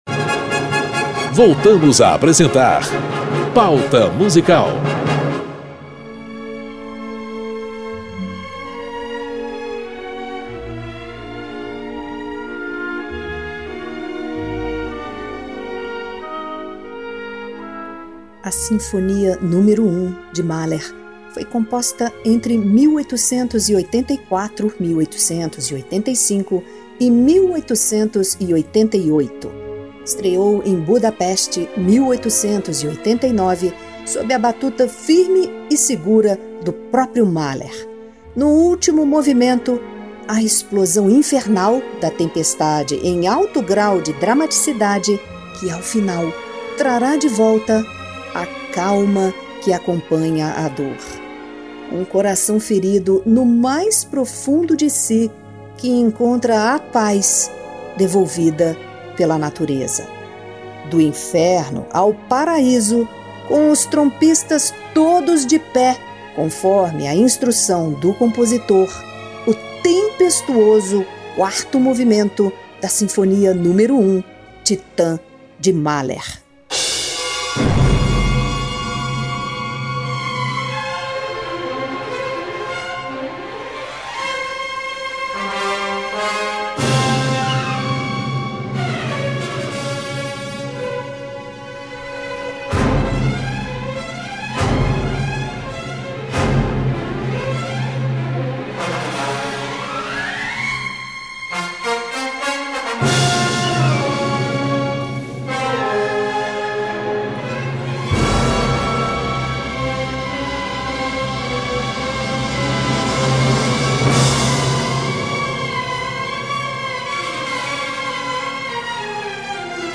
Boston Symphony Orchestra, regida por Erich Leinsdorf, na interpretação da Sinfonia n. 1 em Ré Maior "Titã", do austríaco Gustav Mahler.